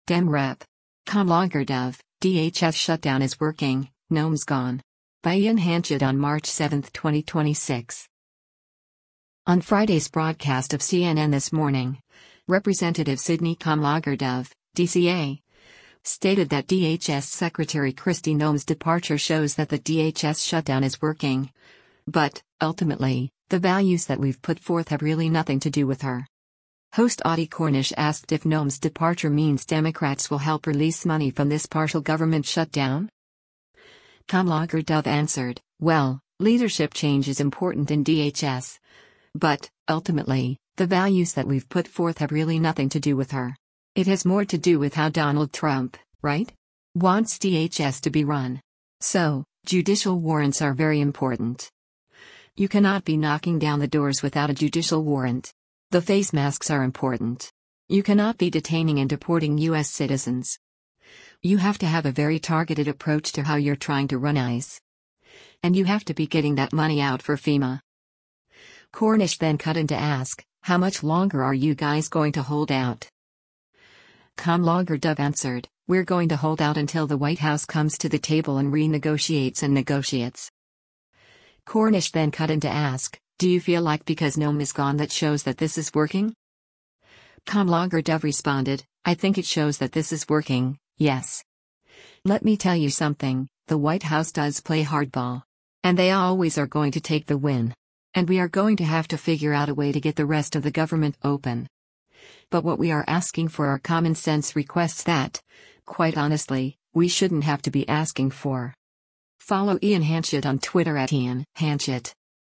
On Friday’s broadcast of “CNN This Morning,” Rep. Sydney Kamlager-Dove (D-CA) stated that DHS Secretary Kristi Noem’s departure shows that the DHS shutdown is “working,” “but, ultimately, the values that we’ve put forth have really nothing to do with her.”
Host Audie Cornish asked if Noem’s departure means “Democrats will help release money from this partial government shutdown?”